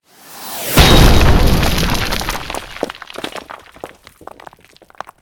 rocketgroundout.ogg